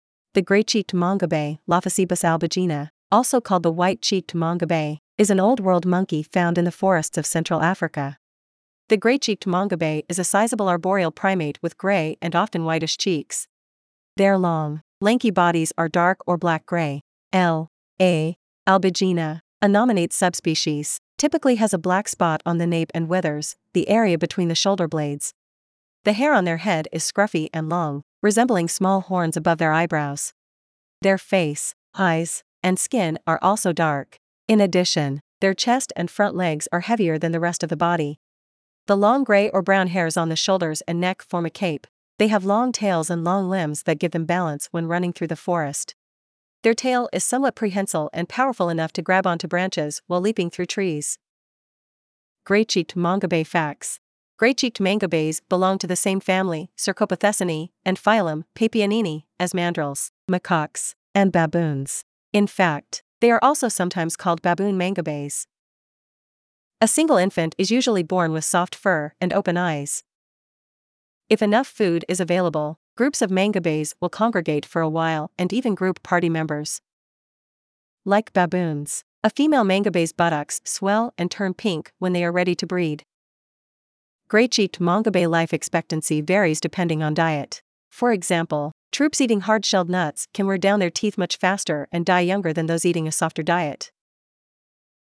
Gray-Cheeked Mangabey
Gray-cheeked-Mangabey.mp3